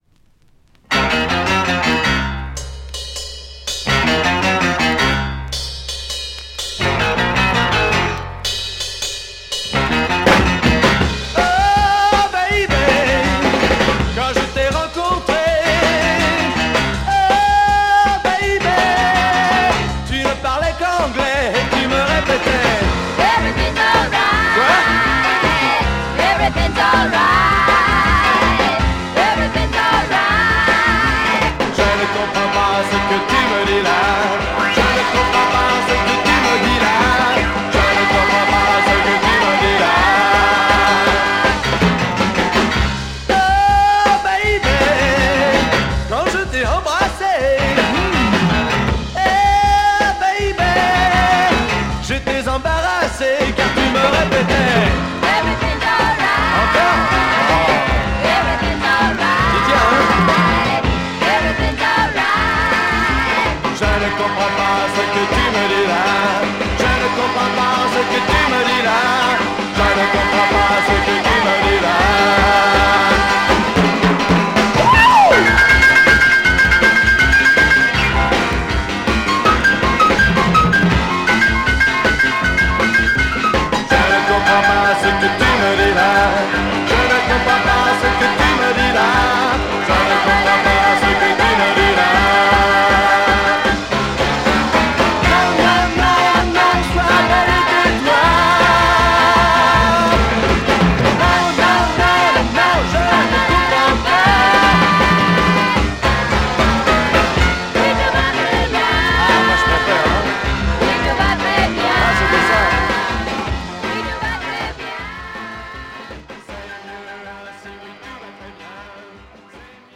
French freakbeat Mod Album
play a cool Mod freakbeat soul sound!